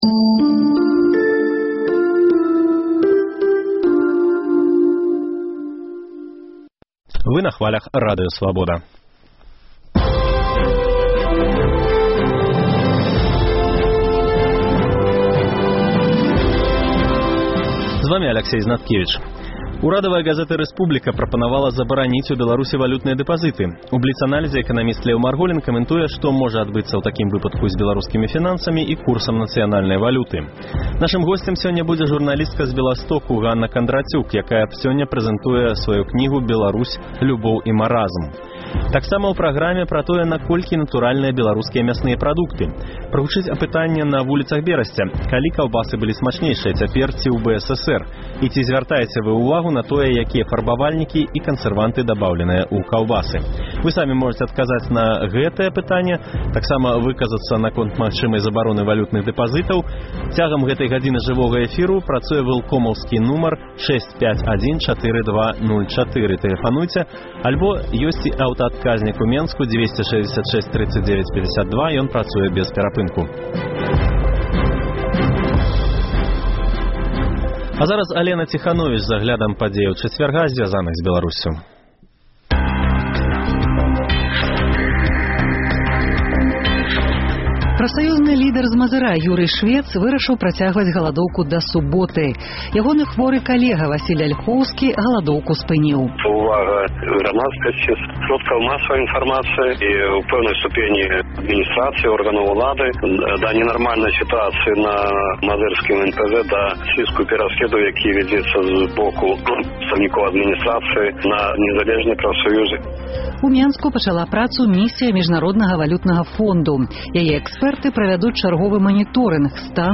Таксама ў праграме – пра тое, наколькі натуральныя беларускія мясныя прадукты. Прагучыць апытаньне на вуліцах Берасьця: Калі каўбасы былі смачнейшыя – цяпер ці ў БССР?